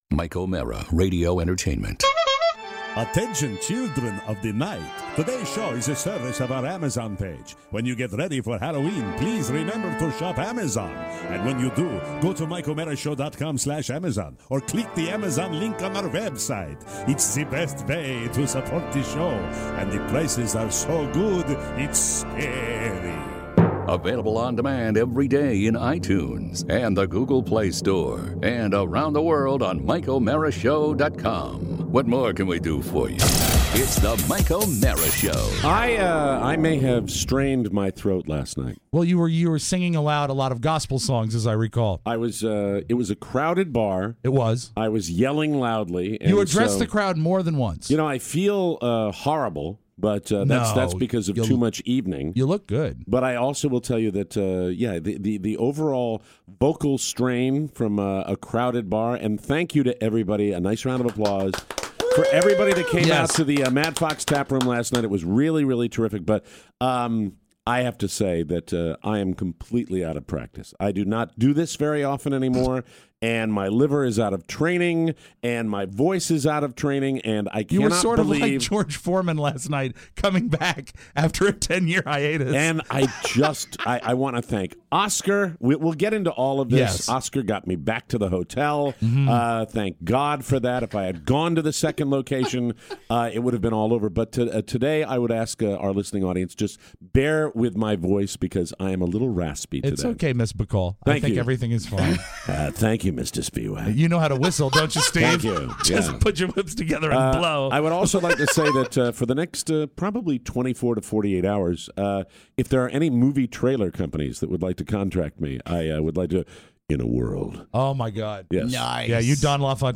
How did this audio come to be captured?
Plus, in studio guests, our Mad Fox recap, a movie date and hockey news.